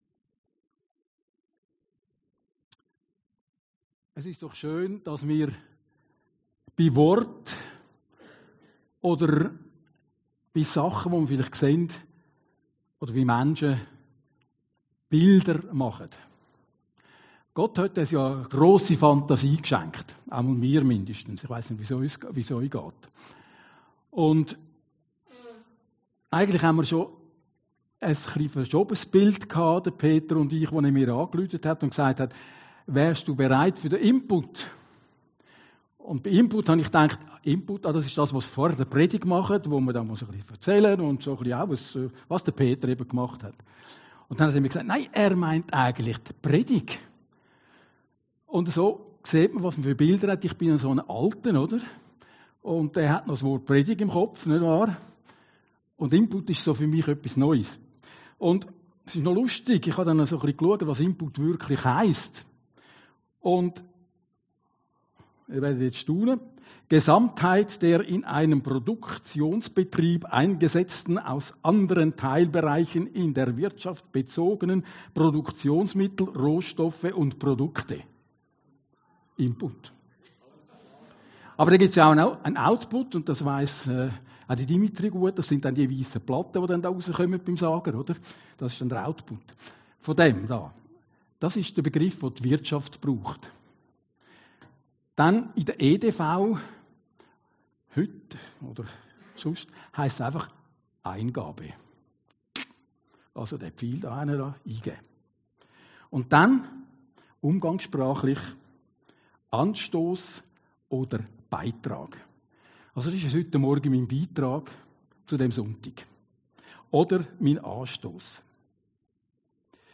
Predigten Heilsarmee Aargau Süd – BILDER